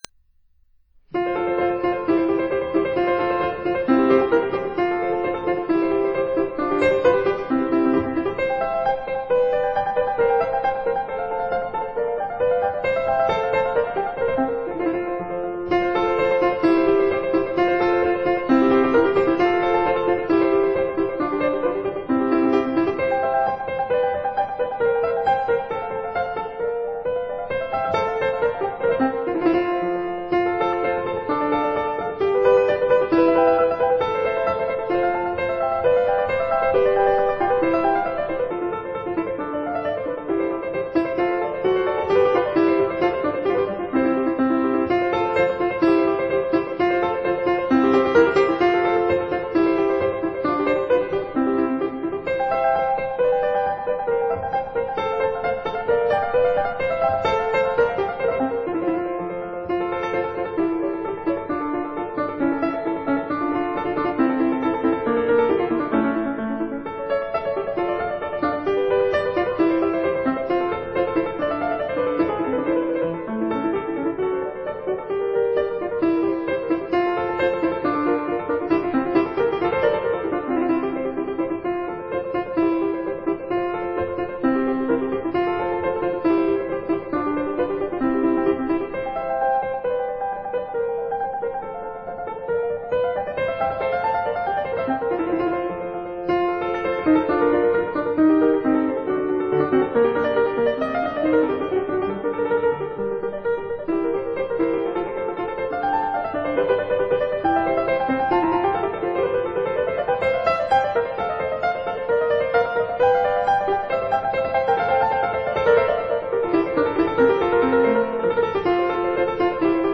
piano
tambour Dawul